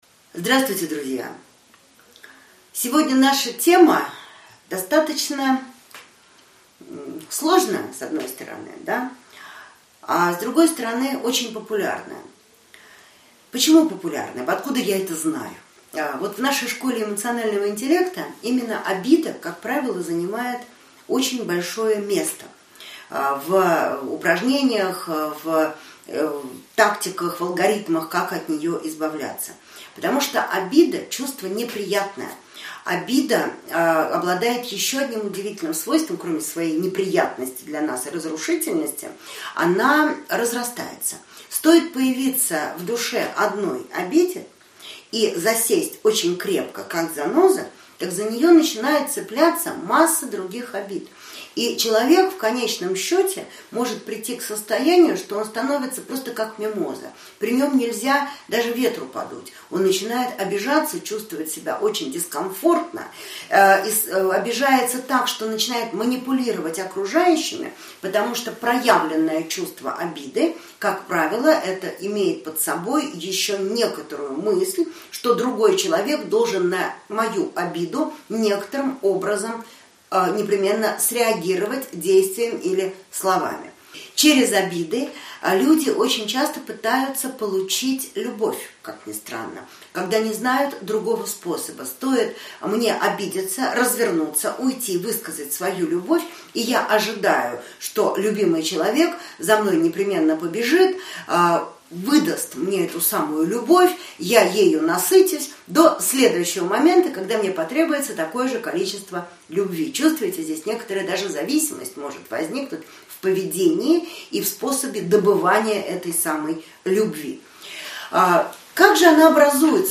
Аудиокнига Краткий курс равновесия. Часть 2 | Библиотека аудиокниг